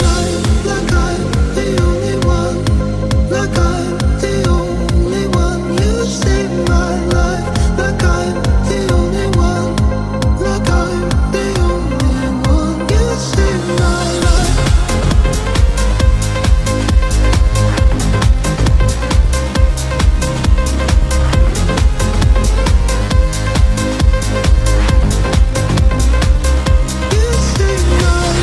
Genere: house,chill,deep,remix,hit